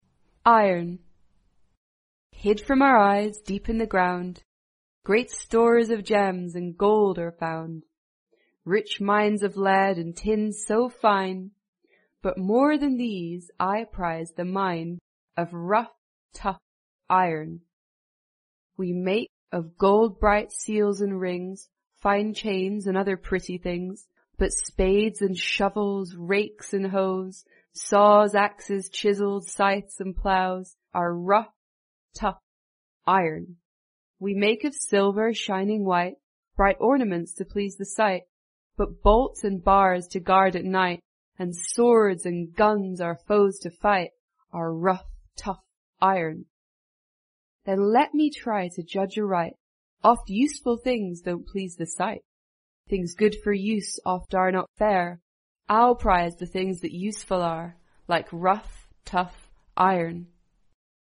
在线英语听力室英国学生科学读本 第107期:铁的听力文件下载,《英国学生科学读本》讲述大自然中的动物、植物等广博的科学知识，犹如一部万物简史。在线英语听力室提供配套英文朗读与双语字幕，帮助读者全面提升英语阅读水平。